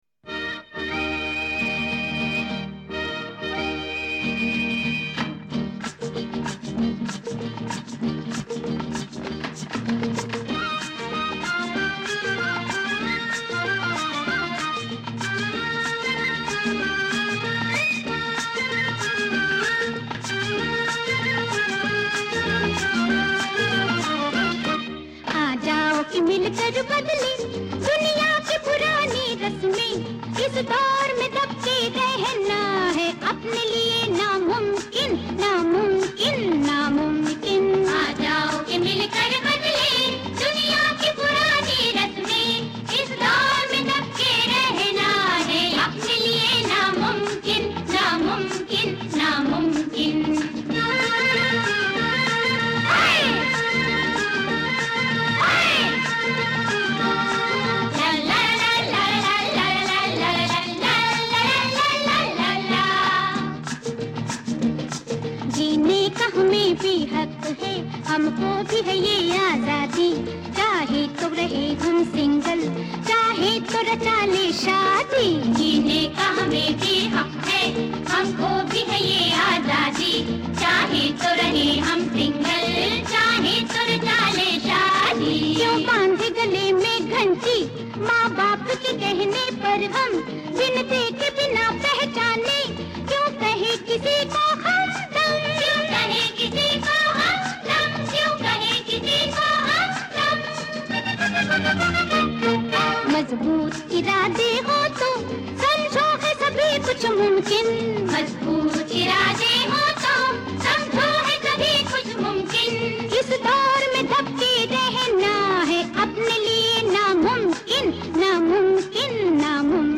Chorus